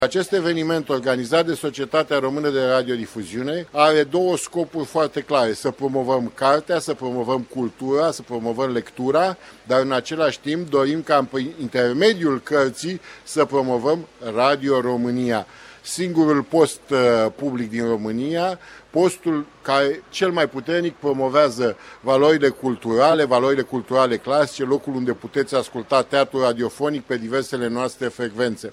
Președintele director general al Societății Române de Radiodifuziune, Georgică Severin, a subliniat la Oradea importanța promovării culturii la Radio România: